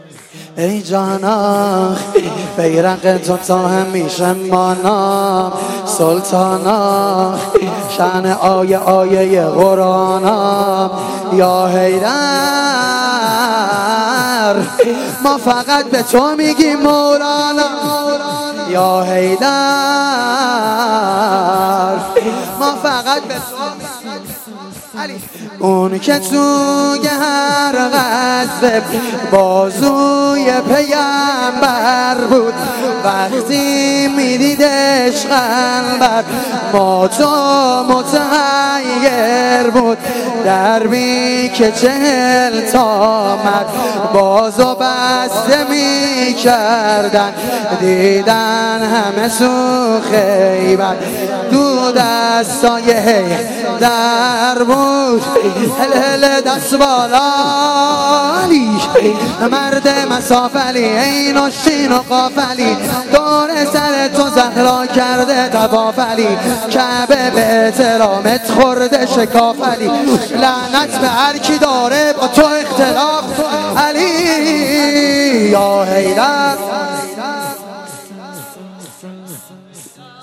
سرود شور | ای جانا
میلاد امام علی (علیه السلام)